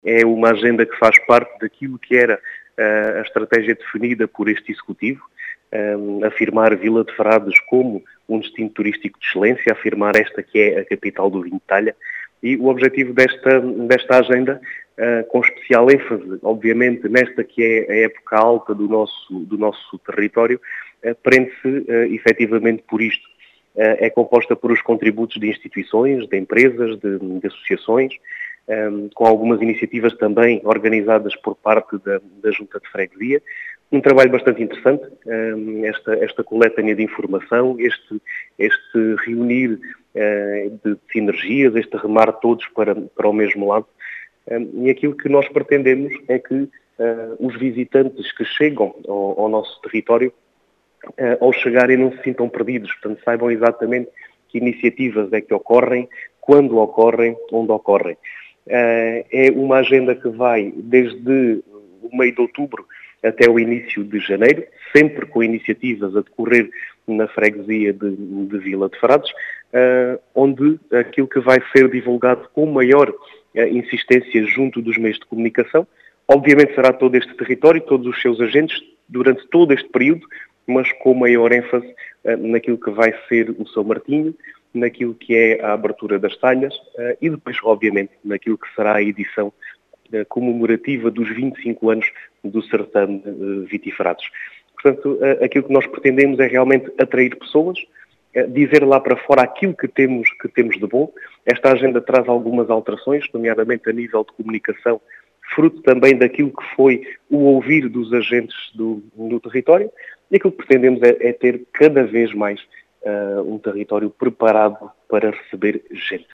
As explicações foram deixadas na Rádio Vidigueira, pelo presidente da junta de freguesia de Vila de Frades, Diogo Conqueiro, que quer afirmar a Capital do Vinho de Talha, como “destino turístico de excelência”.